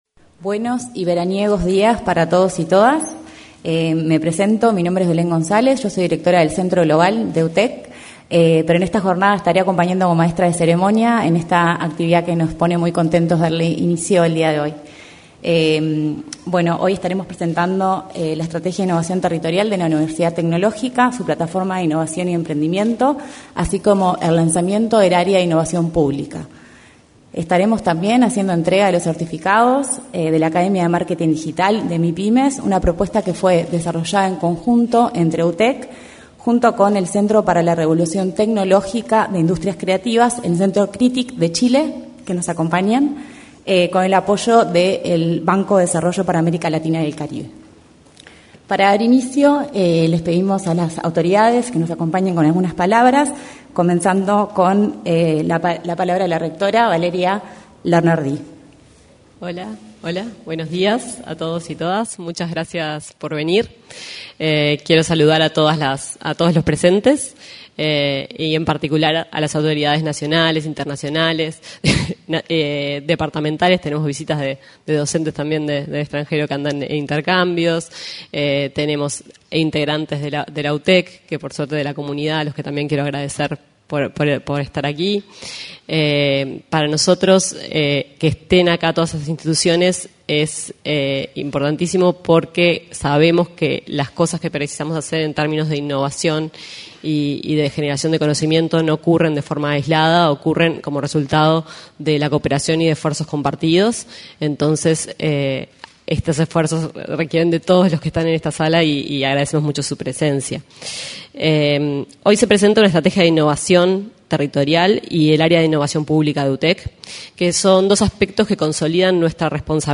Presentación de la Estrategia de Innovación Territorial y el Área de Innovación Pública de la Universidad Tecnológica del Uruguay 05/12/2025 Compartir Facebook X Copiar enlace WhatsApp LinkedIn En el auditorio de la Torre Ejecutiva se realizó la presentación de la Estrategia de Innovación Territorial y el Área de Innovación Pública de la Universidad Tecnológica del Uruguay. En la ocasión, se expresaron la rectora de la Universidad Tecnológica del Uruguay, Valeria Larnaudie, el director de la Oficina Nacional del Servicio Civil, Sergio Pérez, y el prosecretario de la Presidencia de la República, Jorge Díaz.